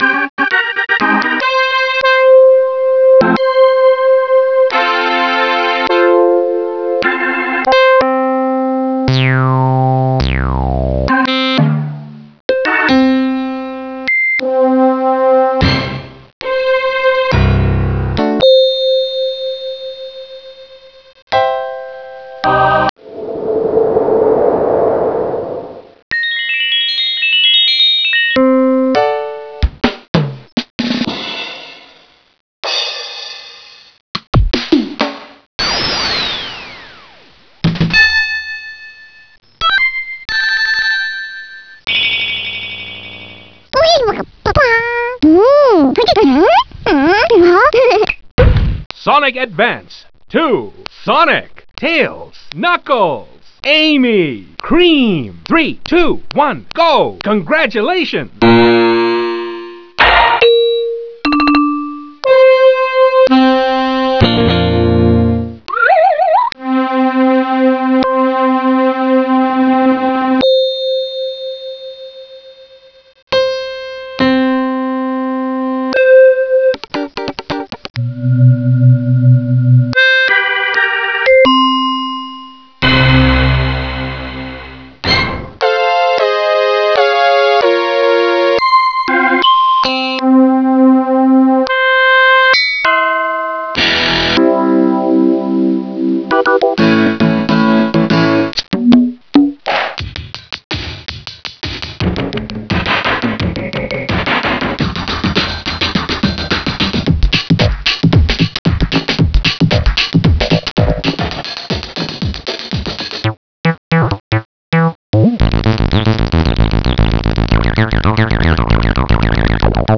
These are instruments used in the game (old audio samples from Sonic Advance plus new 'electronic'-style samples and 'house'-stylsih rythmic loops), a few sound effects and still the chao voice, saying the same weird things like 'ohimok'p pop' or similar :
sa2sounds.wav